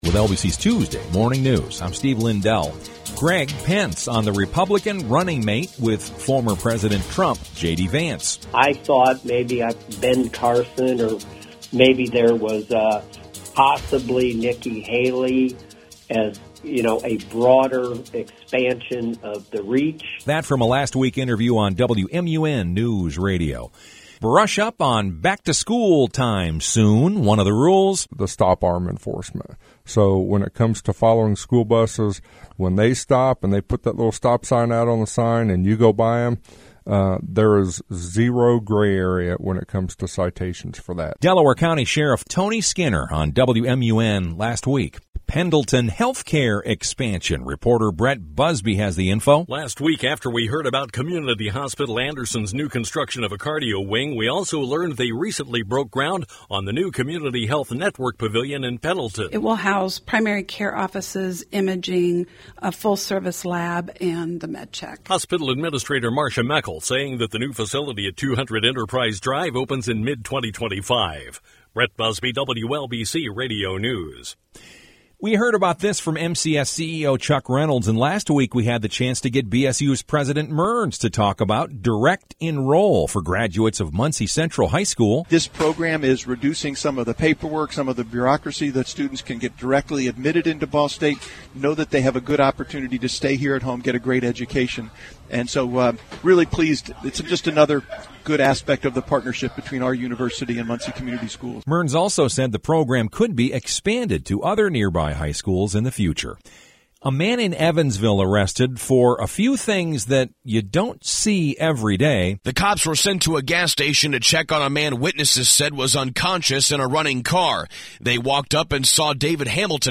Best Radio NewscastWLBC-FM (Muncie) – Morning Report July 23
Best locally originated newscast.